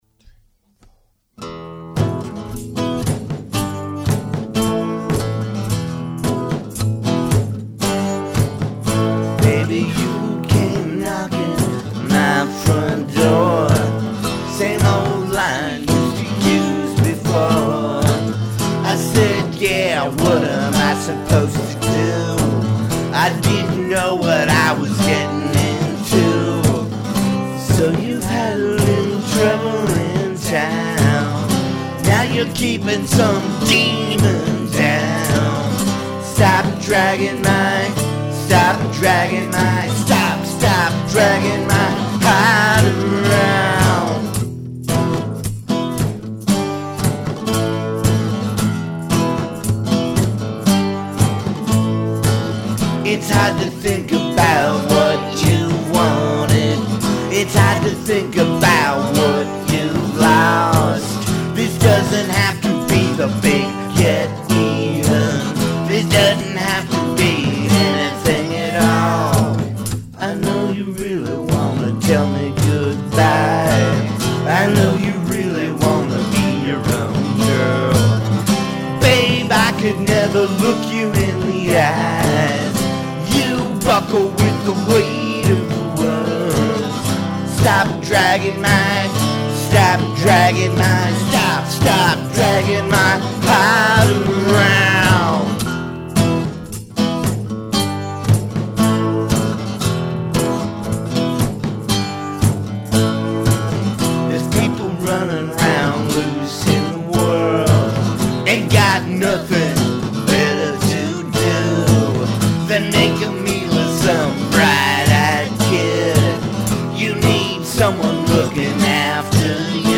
cover..again